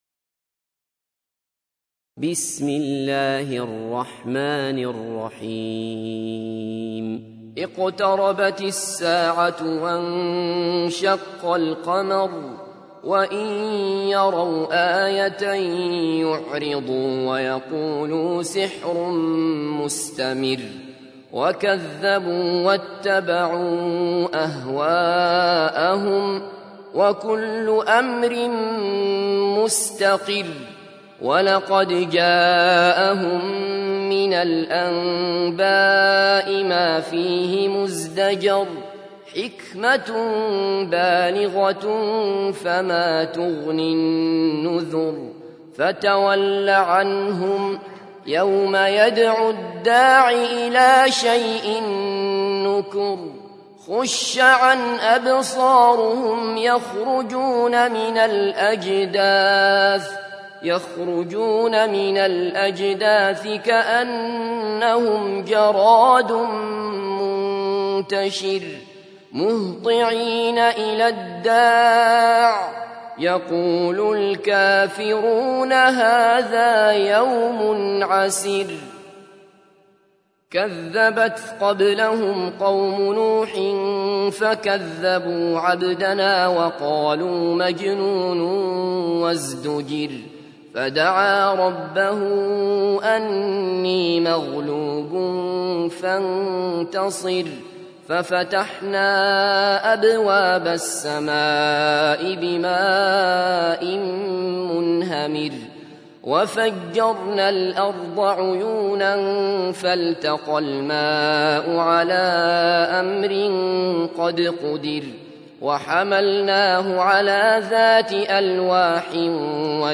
تحميل : 54. سورة القمر / القارئ عبد الله بصفر / القرآن الكريم / موقع يا حسين